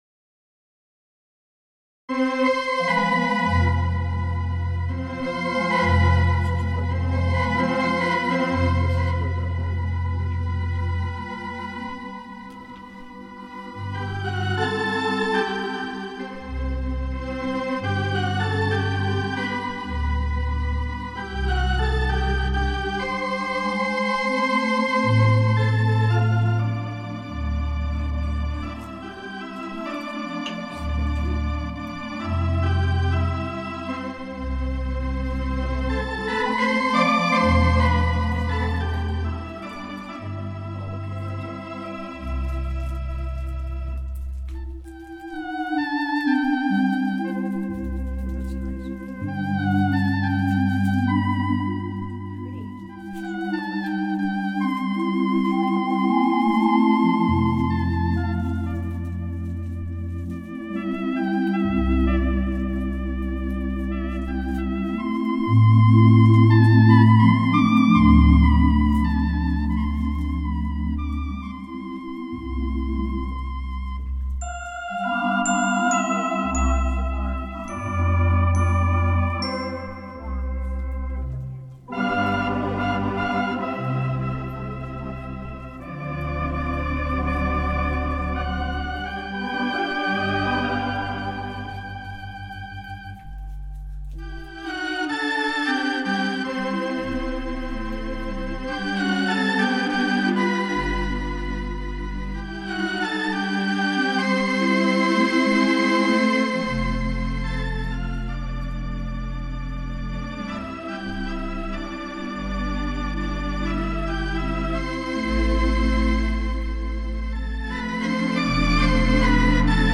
Ridgecrest Organ Club Bi-Weekly Meetings
Roland Atelier AT90-SL Digital Residence Organ.
In some of the tracks, listed below, you can hear cups being sat down on the table.
Also, since this is totally unrehearsed music, there are a number of mistakes, but hey, it's live and impromptue.